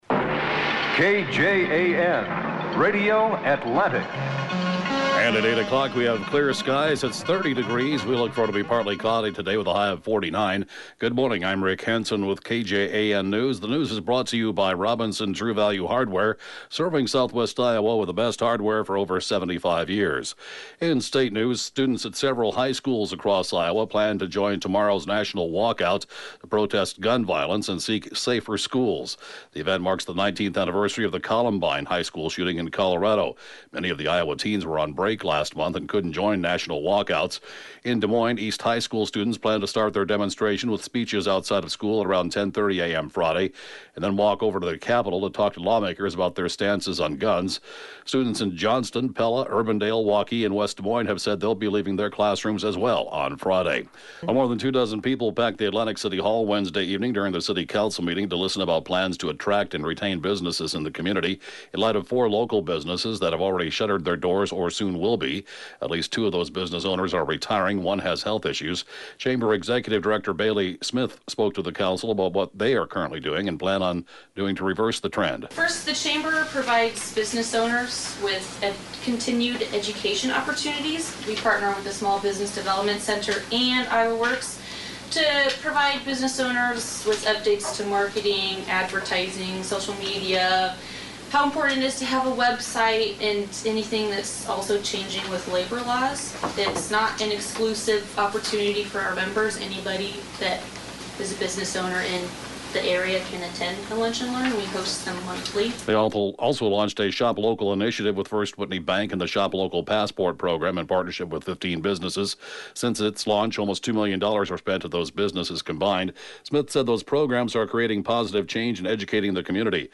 (Podcast) KJAN 8-a.m. News, 4/19/2018